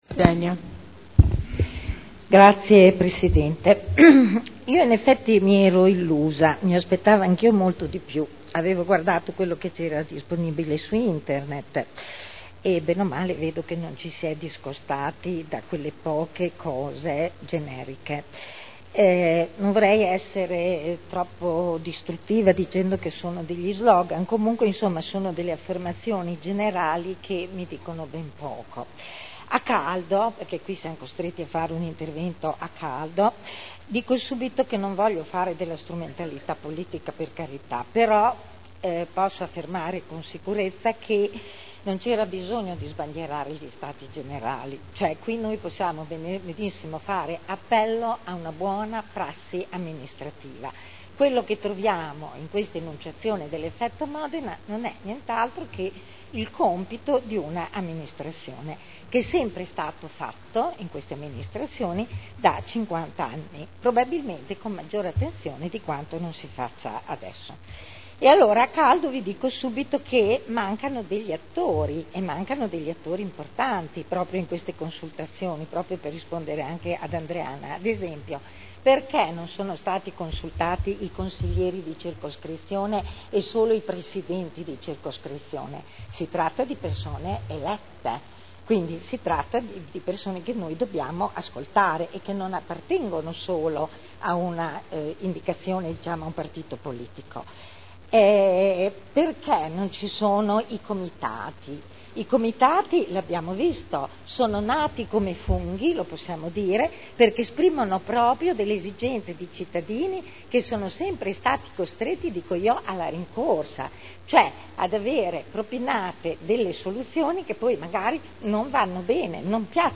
Eugenia Rossi — Sito Audio Consiglio Comunale